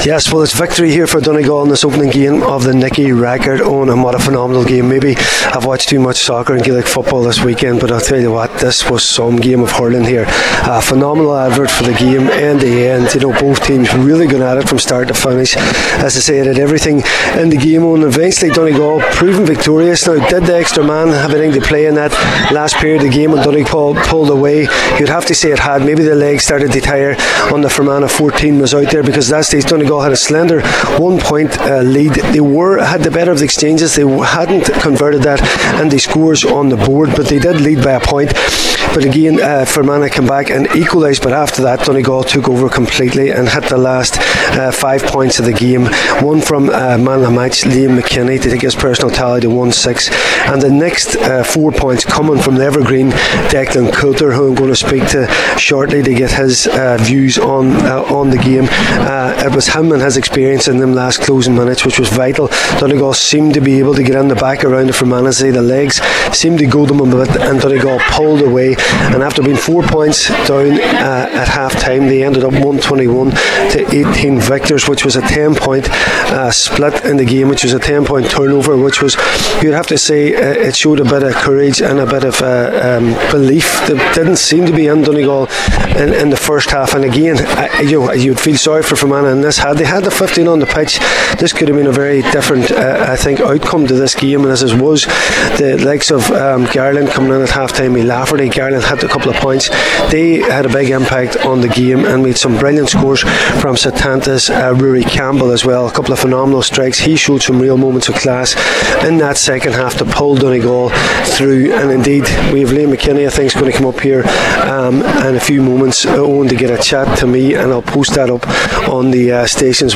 full time report from O’Donnell Park…